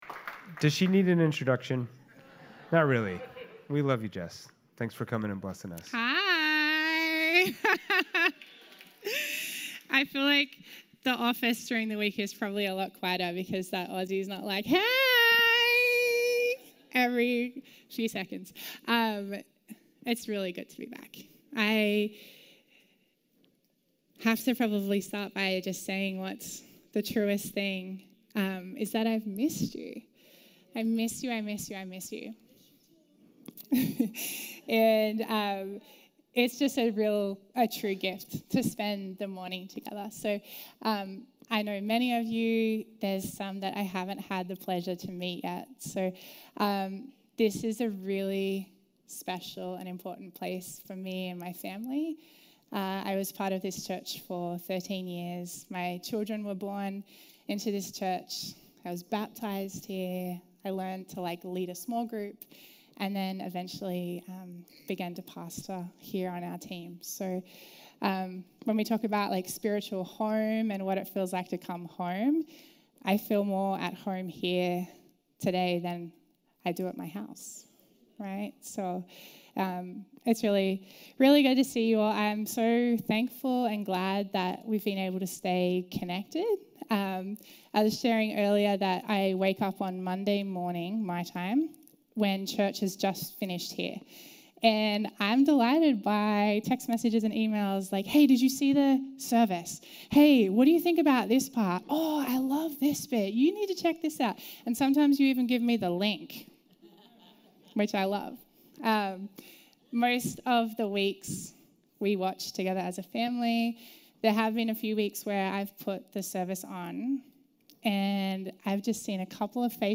visits us from Australia to deliver this morning's message.